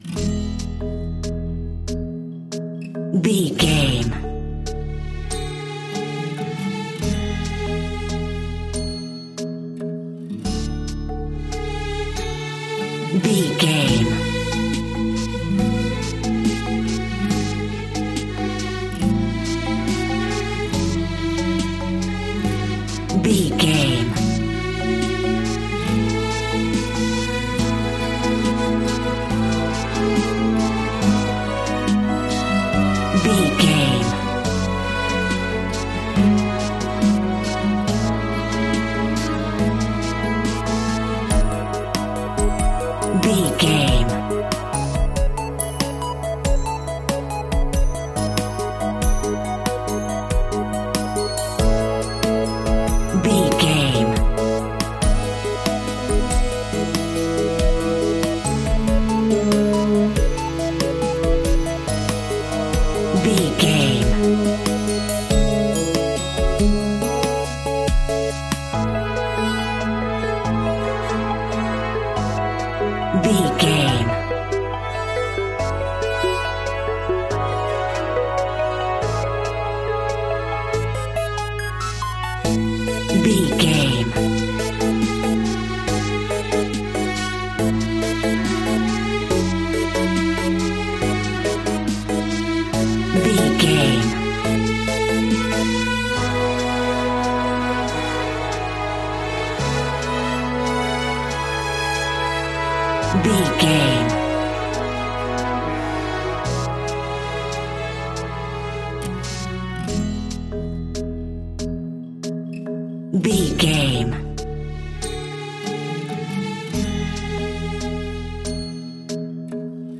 Ionian/Major
bright
cheerful/happy
driving
energetic
playful
tranquil
acoustic guitar
bassoon
brass
drums
sleigh bells
strings
trumpet
synthesiser
violin
8 bit
orchestral